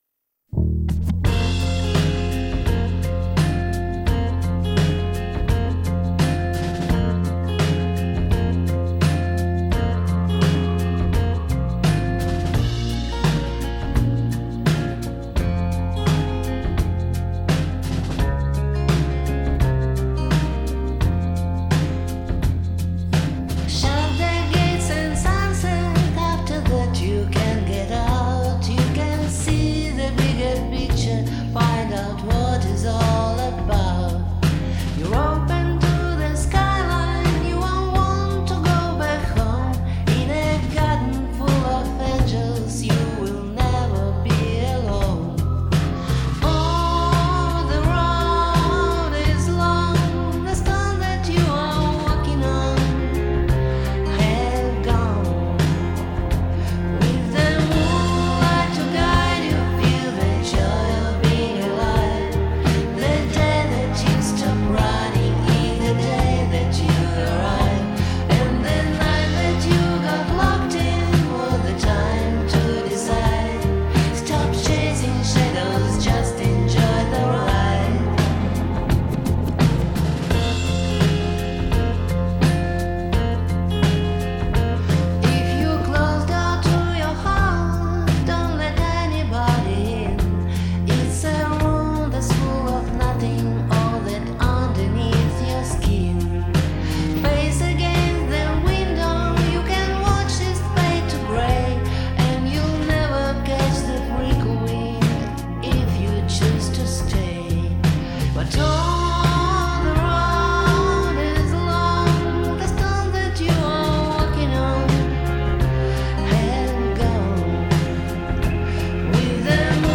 Второй голос, да, мой.